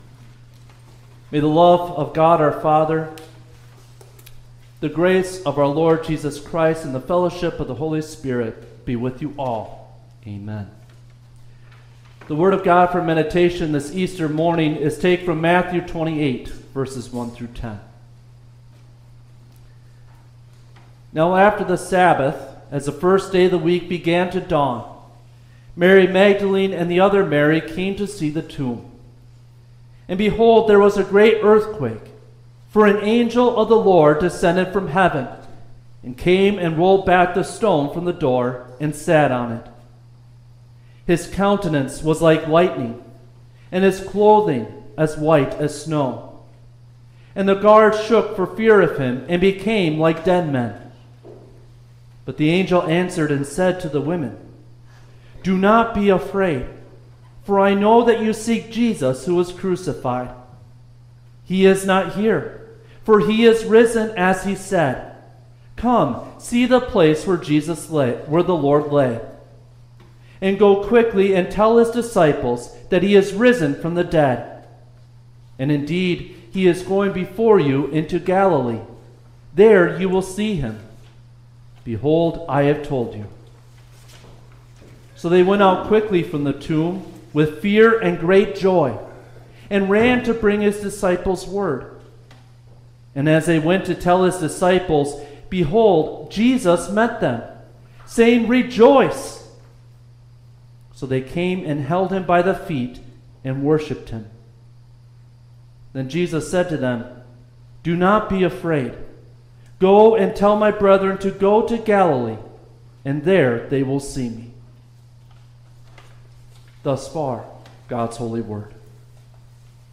Easter-Sunday-Service-_-April-5-2026_.mp3